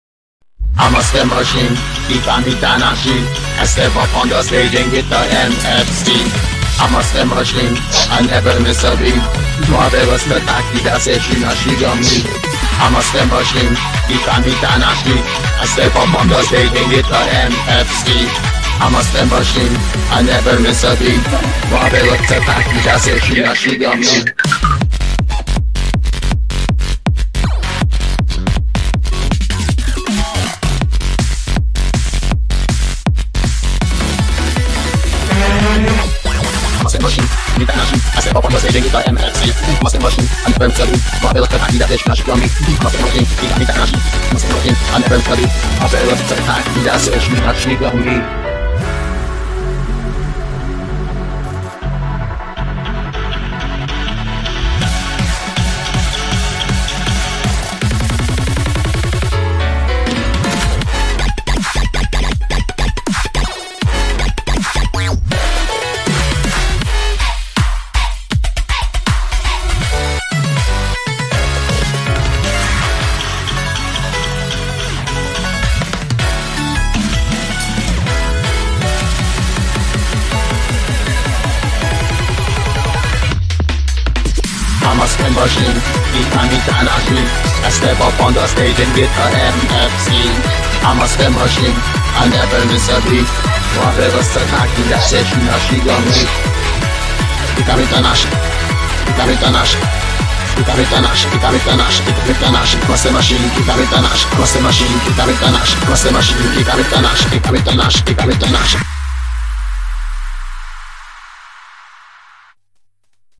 BPM160
Audio QualityPerfect (High Quality)
Mashup